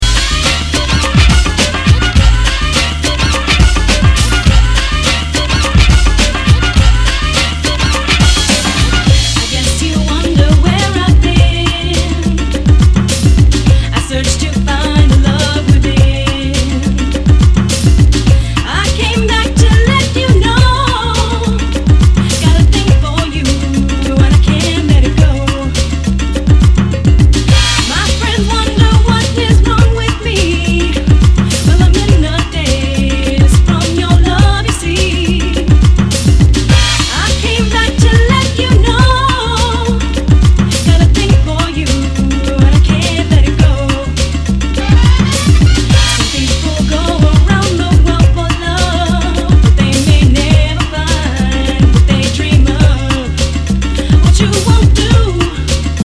Nu- Jazz/BREAK BEATS
全体にチリノイズが入ります